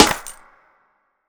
HFMSnare3.wav